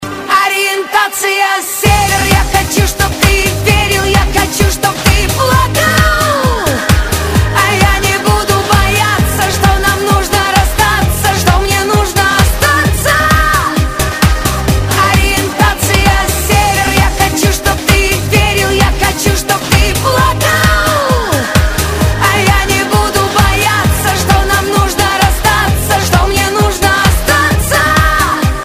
Романтические рингтоны
Громкие рингтоны